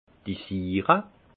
Ville Prononciation 68 Munster